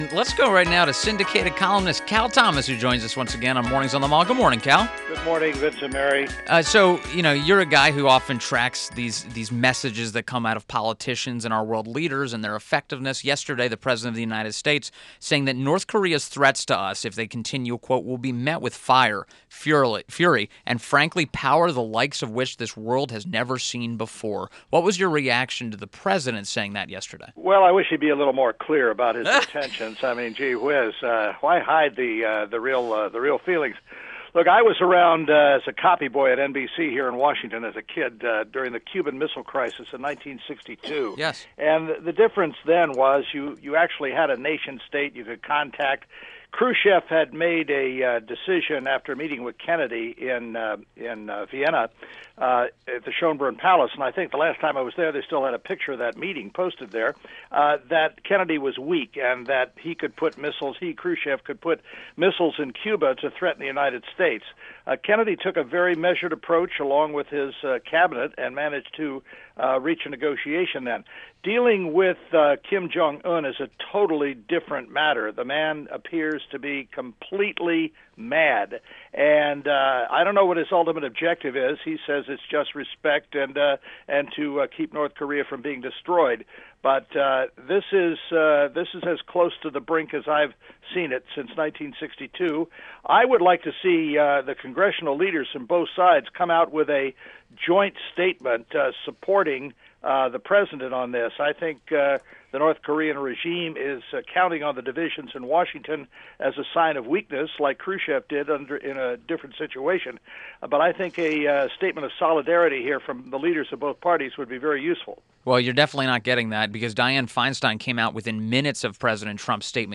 INTERVIEW – CAL THOMAS – Syndicated Columnist